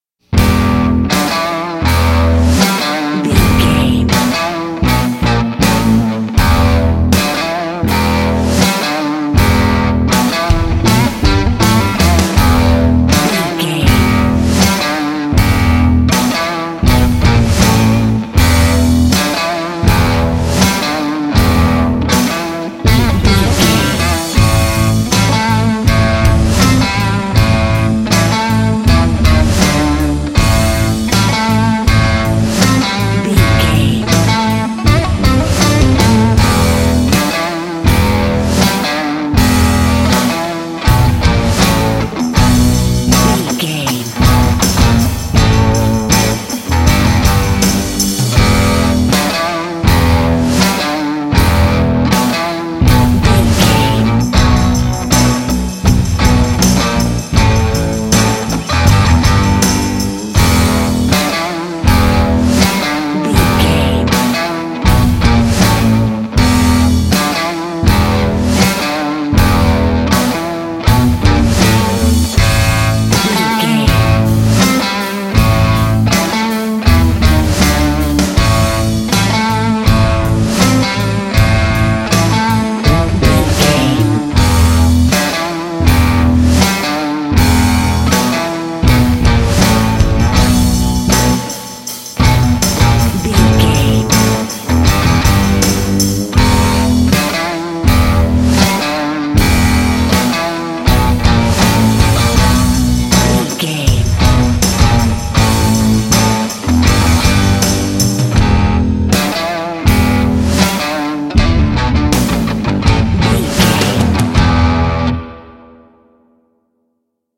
Epic / Action
Aeolian/Minor
bass guitar
drum machine
electric guitar
percussion
aggressive
epic
intense
groovy
energetic
heavy